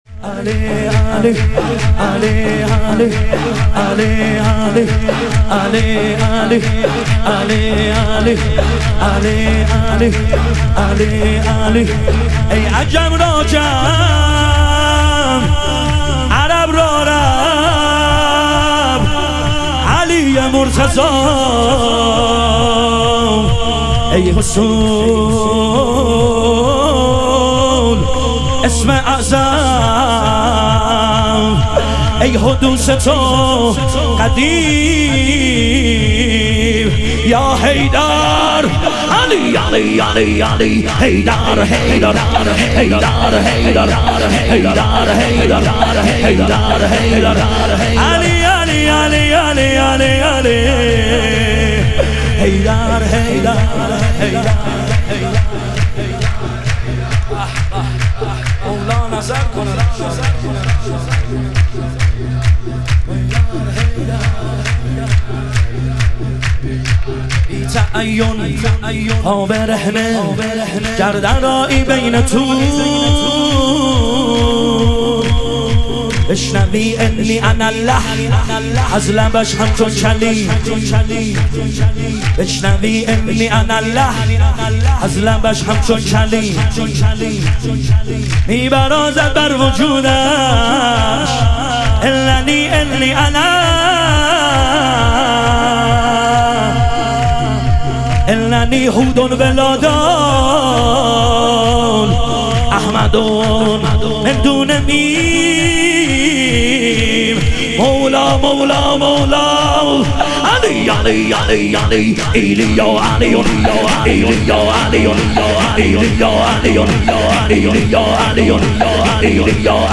ظهور وجود مقدس امام جواد و حضرت علی اصغر علیهم السلام - شور